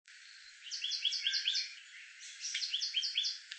8-4墾丁紅嘴黑鵯1.mp3
紅嘴黑鵯(台灣亞種) Hypsipetes leucocephalus nigerrimus
錄音地點 屏東縣 恆春鎮 墾丁青年活動中心
錄音環境 海岸林
行為描述 鳴叫
錄音: 廠牌 Denon Portable IC Recorder 型號 DN-F20R 收音: 廠牌 Sennheiser 型號 ME 67